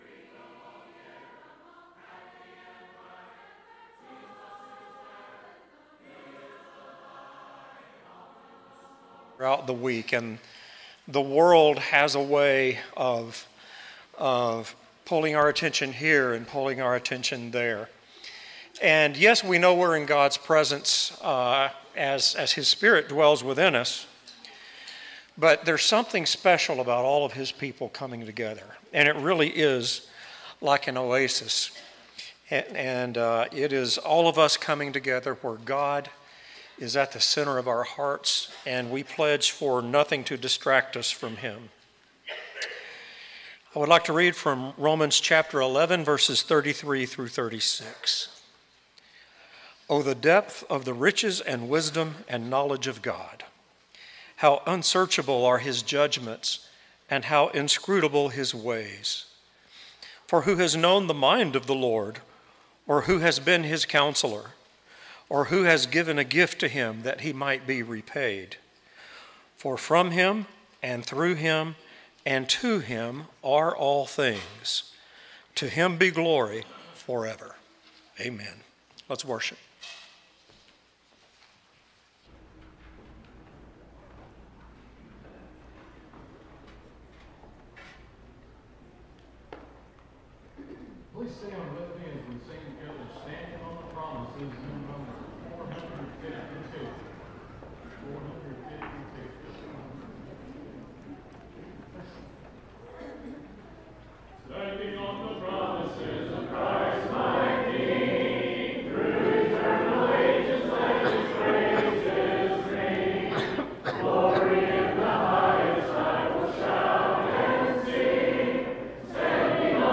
Matthew 20:28, English Standard Version Series: Sunday AM Service